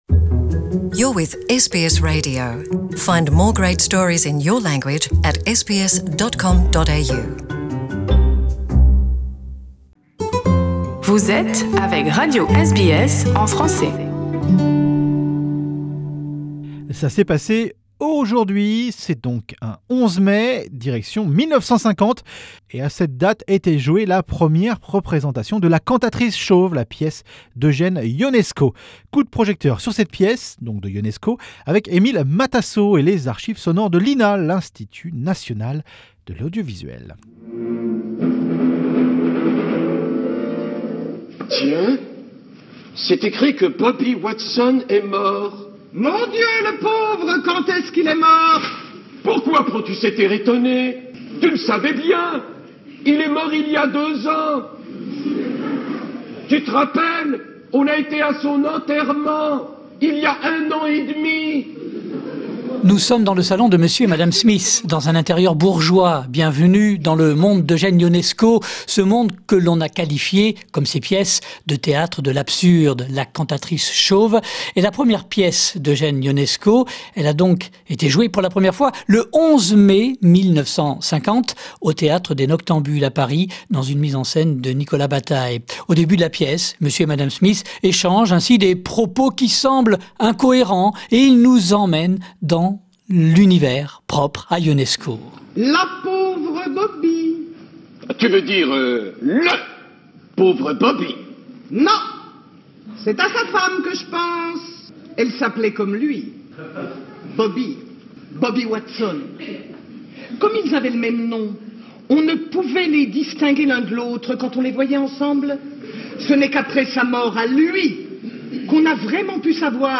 Notre rubrique retro à présent...nous sommes le 11 mai….c’est arrivé ce jour là, le 11 mai 1950 était joué la première représentation de « La Cantatrice Chauve » la pièce d’Eugène Ionesco…. Coup de projecteur sur cette pièce...de Ionesco ...avec les archives sonores de l’INA...l’Institut National de l’Audiovisuel..